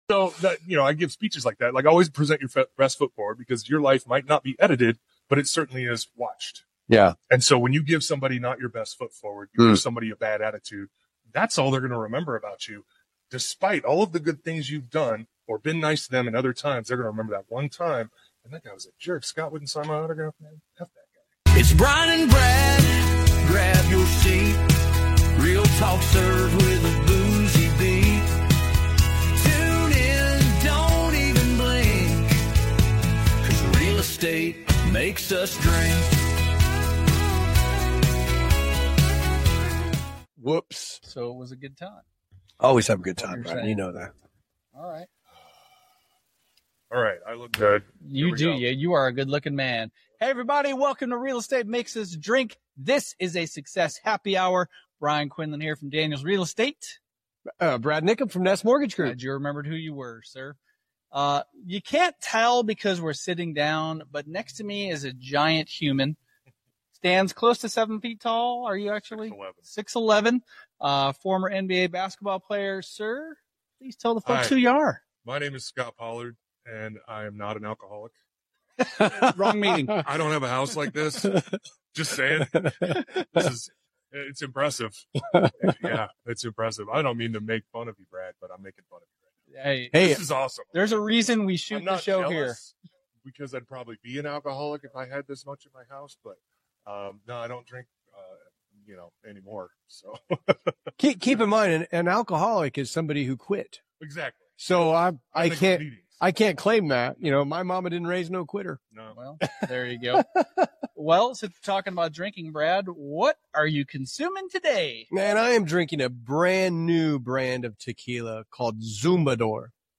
This is Part 1 of a two-part interview where Scot shares the wild ride from growing up in a giant family, to becoming a Kansas Jayhawk legend, to being drafted by the Detroit Pistons in the first round of the 1997 NBA Draft.